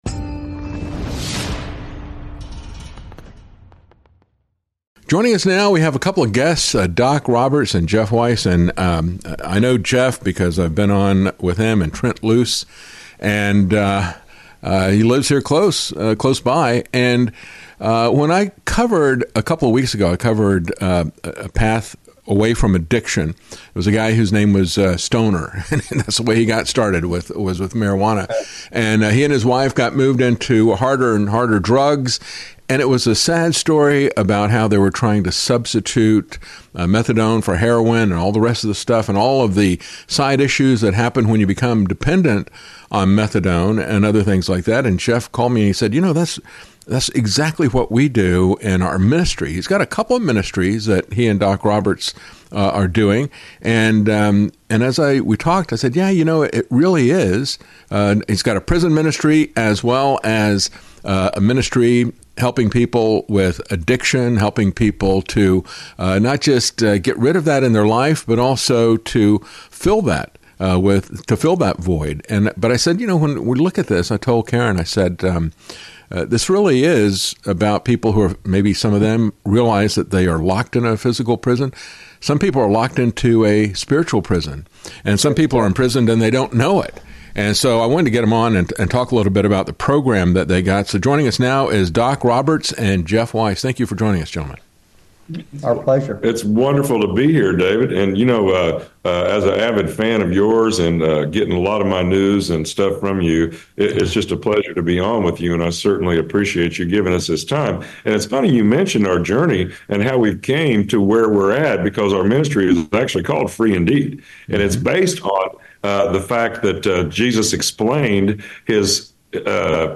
interview-free-indeed-from-addiction-and-much-more.mp3